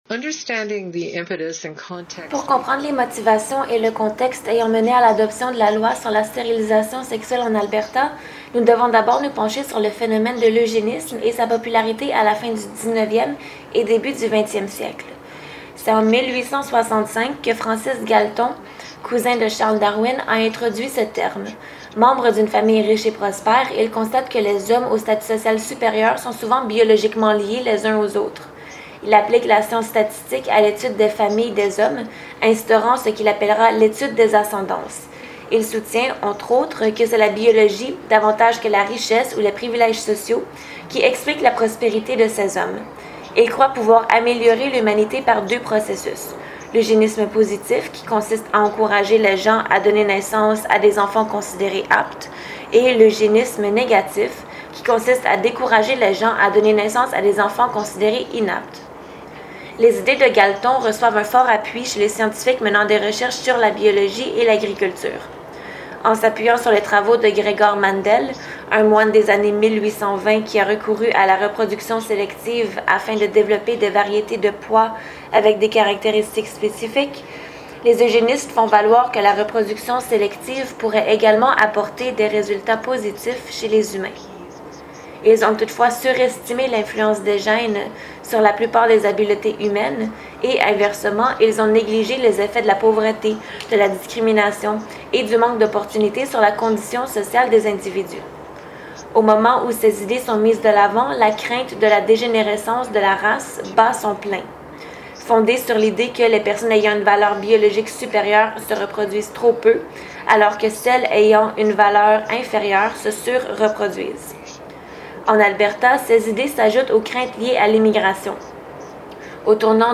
Cet ensemble de documents historiques et de commentaires audio permet aux étudiants de démêler les raisonnements derrière la Loi de la stérilisation sexuelle de 1928 en Alberta ainsi que ses amendements.